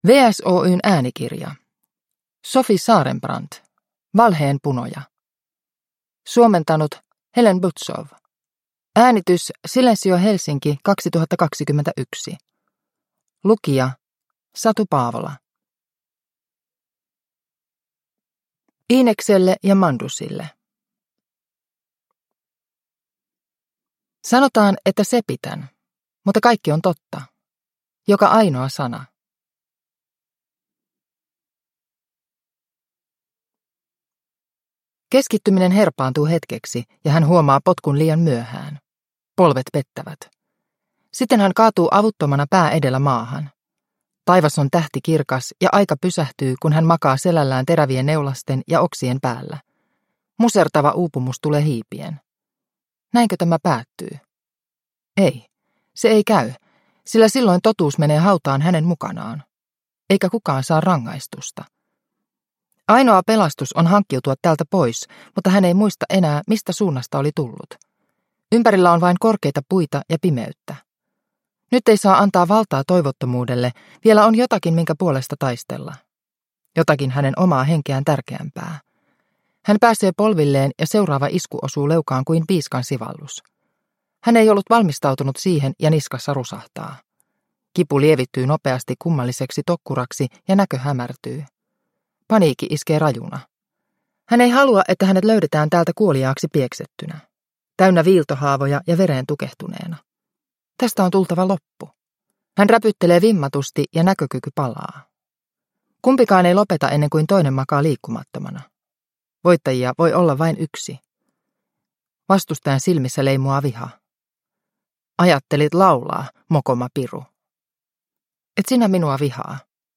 Valheenpunoja (ljudbok) av Sofie Sarenbrant